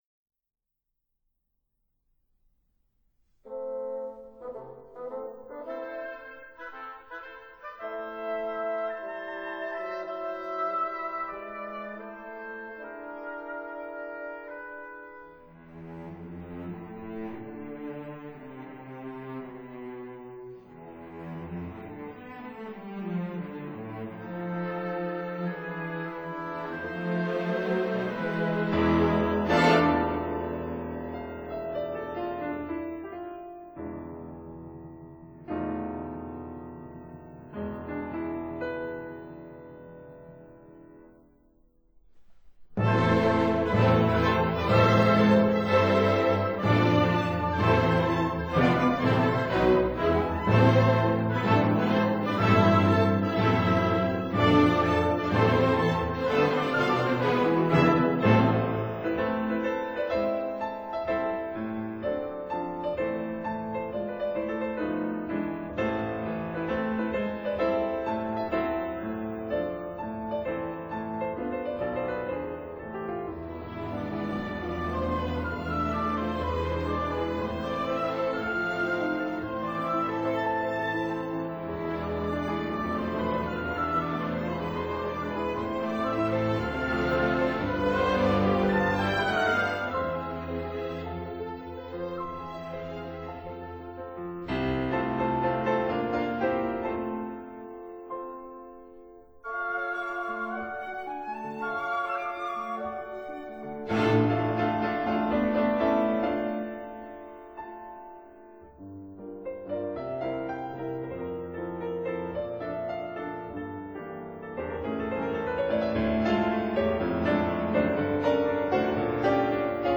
Piano Concerto No. 1 in A minor
Variations for Piano and Orchestra